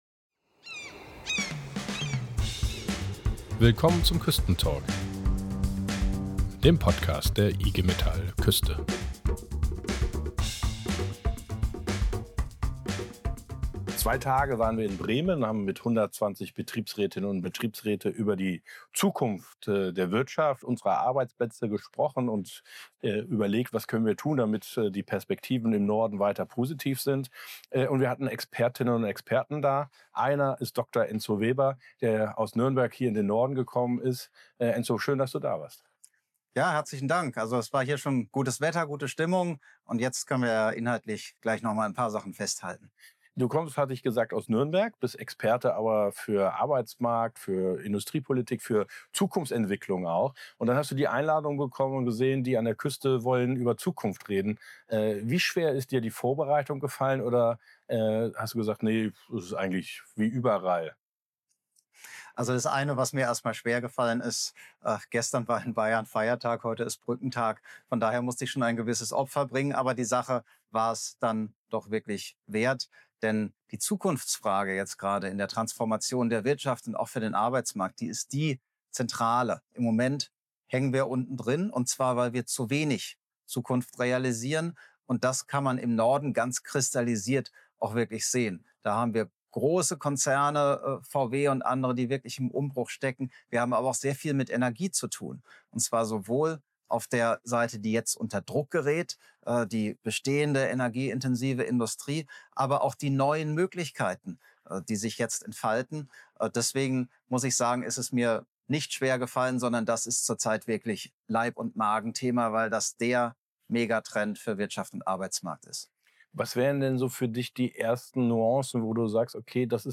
Ein Gespräch über Chancen, Wandel und die Zukunft der Arbeit.